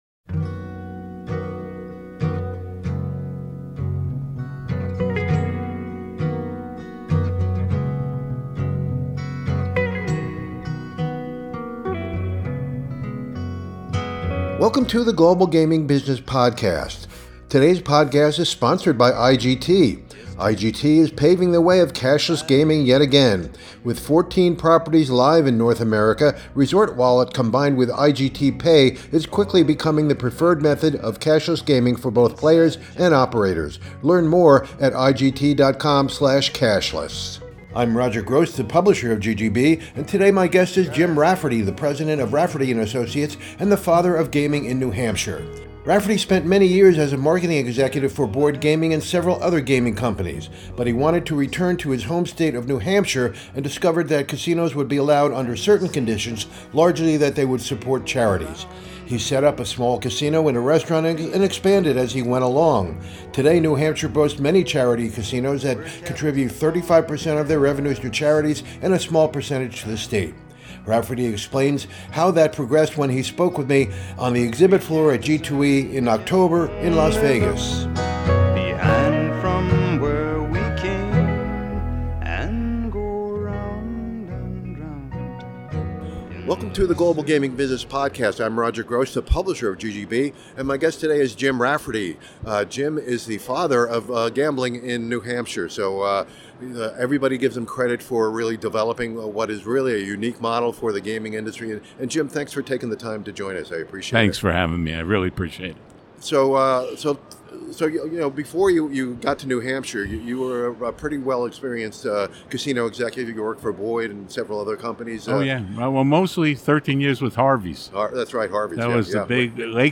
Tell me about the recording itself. on the exhibit floor at G2E in October in Las Vegas